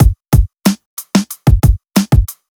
FK092BEAT4-R.wav